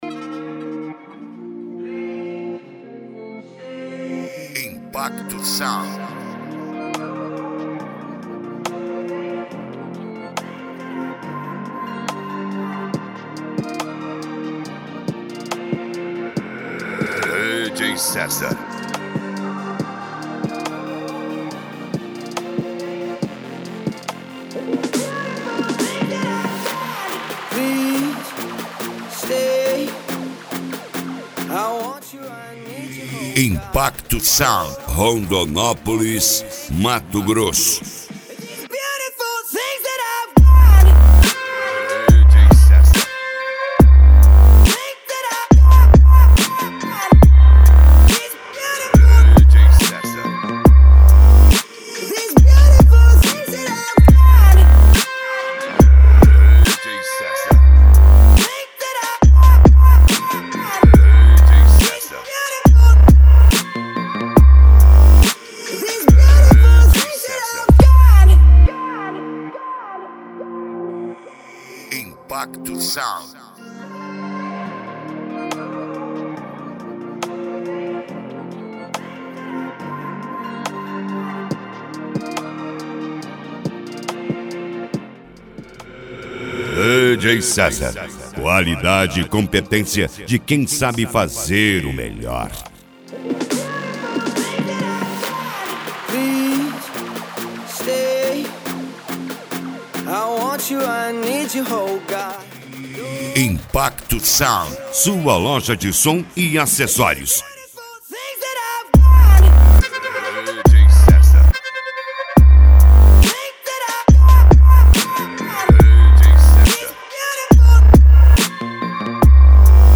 Funk Nejo
Mega Funk